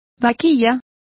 Complete with pronunciation of the translation of heifers.